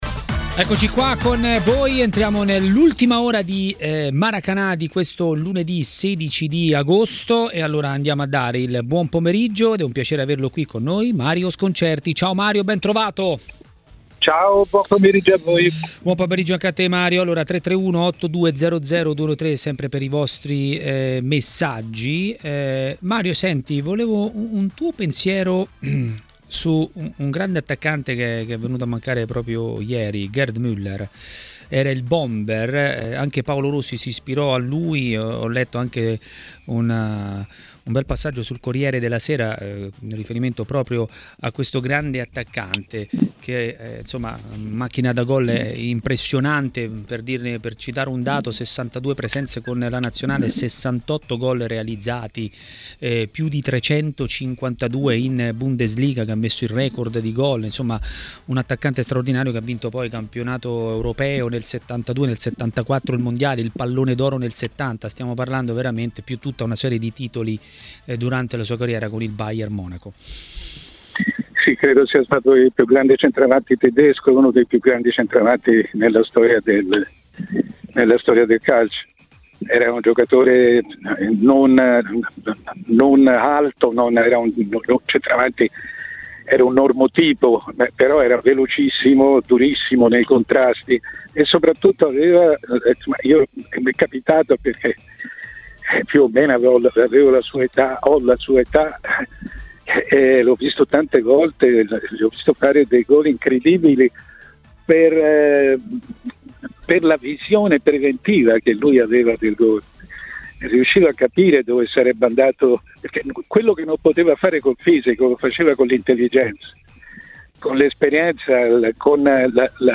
Mario Sconcerti, decano del giornalismo sportivo e opinionista di TMW Radio, ha così parlato durante Maracanà. Molti tifosi della Roma avrebbero preferito un giocatore alla Belotti in attacco.